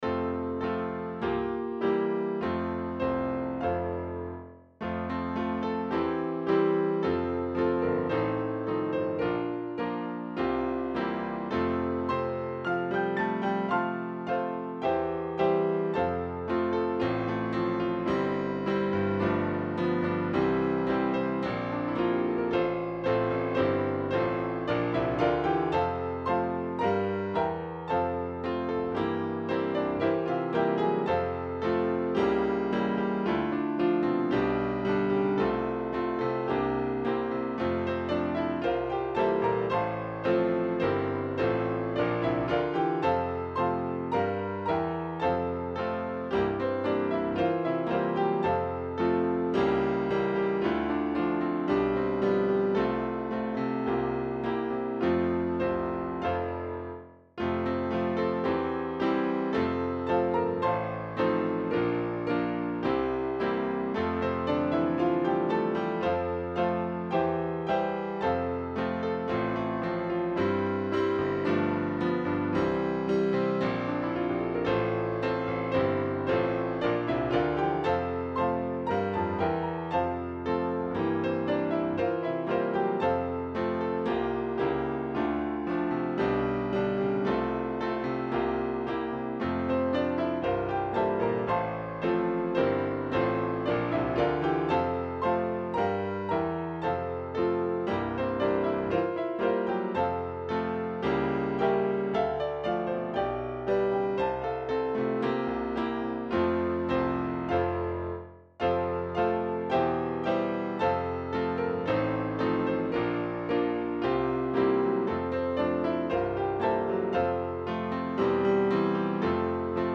Key: F